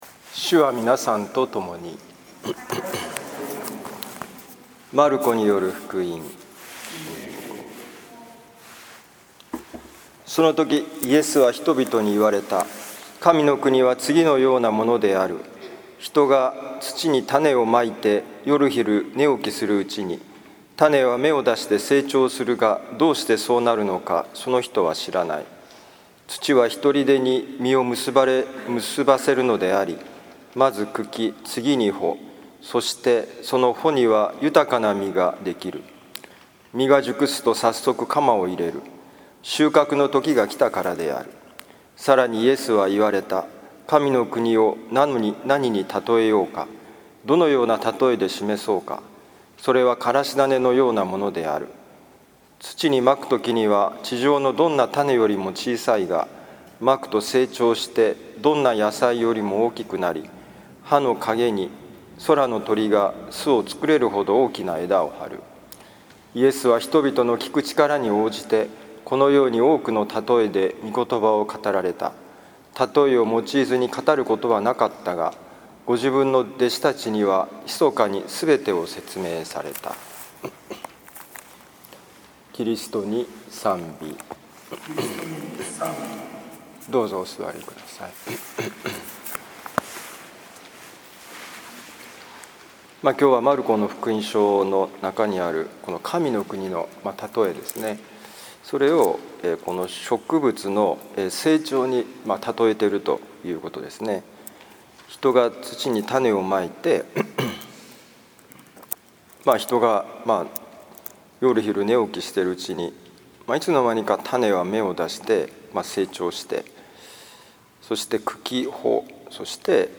マルコ福音書4章26-34節「実りは他者のために」2021年6月13日年間第11主日（B年）聖イグナチオ教会
今日の福音書朗読とお説教の聞きどころ